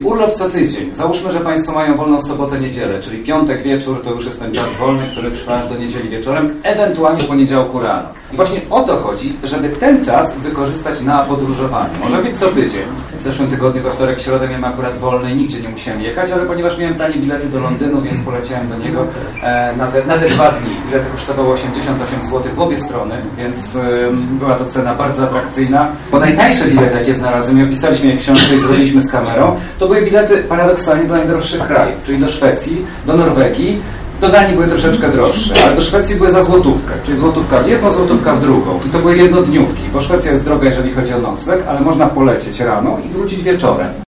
W Miejskiej i Powiatowej Bbiliotece Publicznej zebrało się wielu mieszkańców miasta i okolic by wysłuchać fascynującej opowieści o krajowych i zagranicznych wojażach.